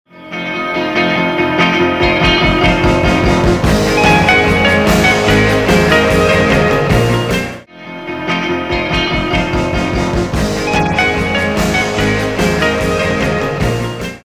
That's some of the worst phasey crap I've ever heard.